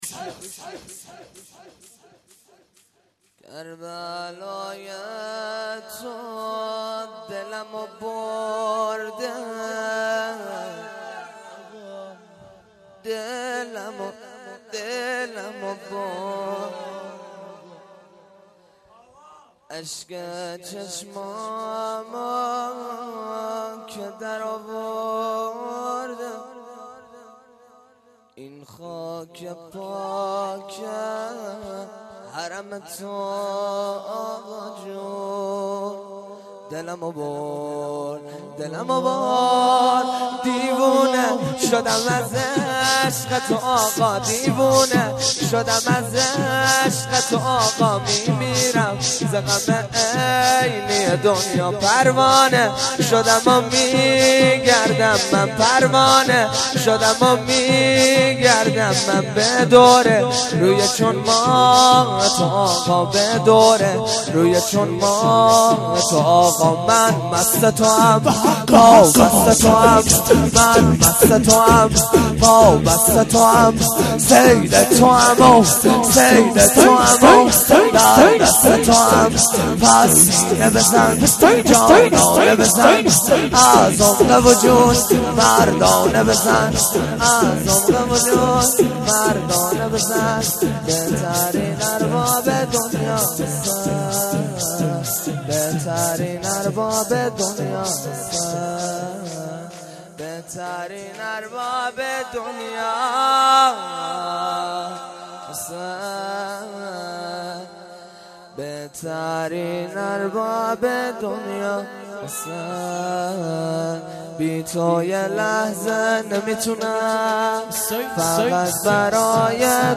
شور کربلای تو دلمو برد
در محفل زینیون مشهدالرضا (ع)